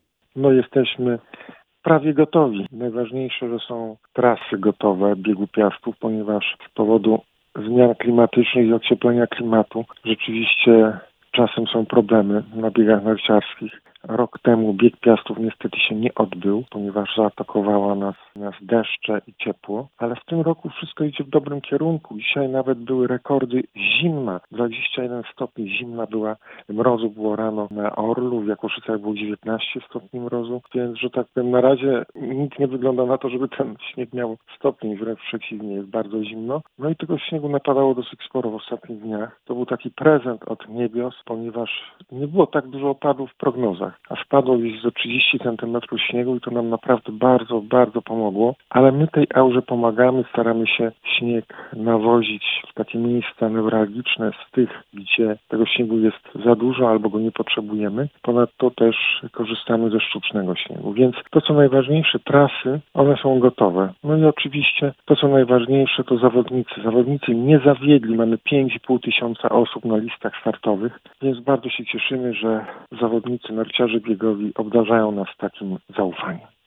Na całą rozmowę zapraszamy w audycji „Sportowy kwadrans” dziś o 15:30!